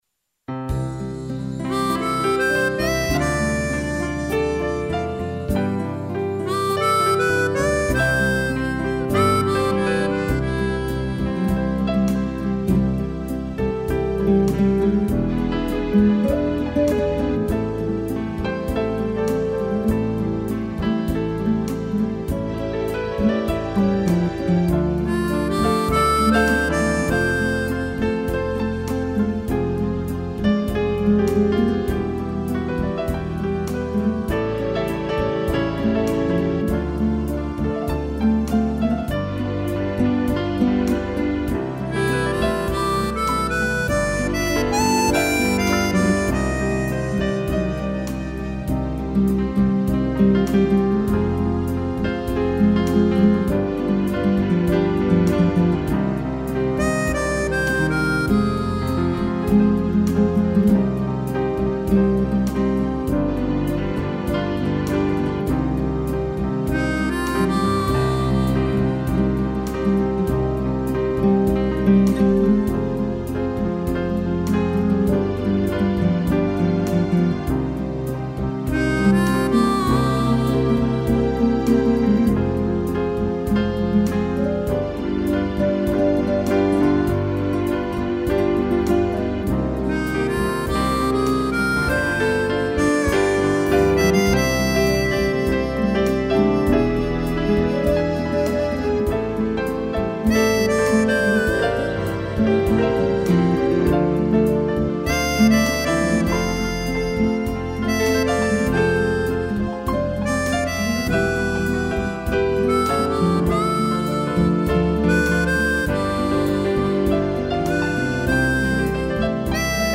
piano e gaita
(instrumental)